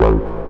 tekTTE63021acid-A.wav